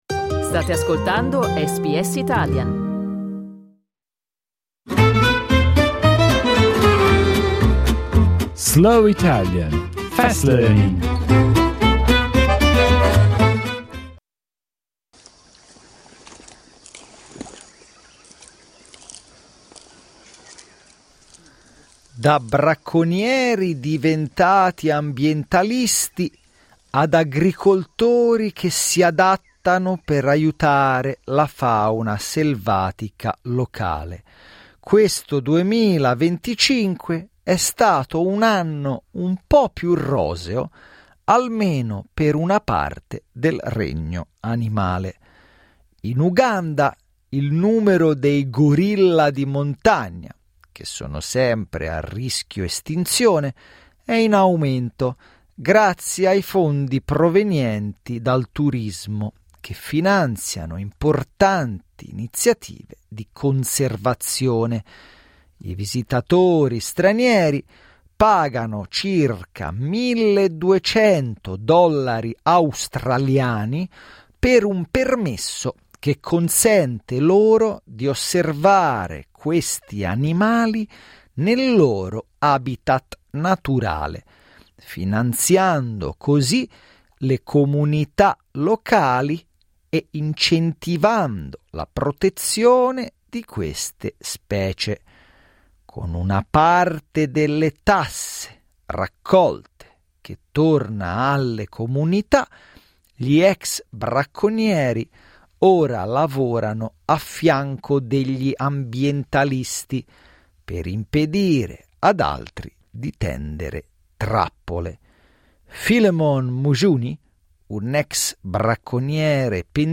Hear news updates from the journalists at SBS Italian, spoken at a slower pace.